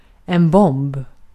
Ääntäminen
Ääntäminen Haettu sana löytyi näillä lähdekielillä: ruotsi Käännös Ääninäyte Substantiivit 1. bomb US Artikkeli: en .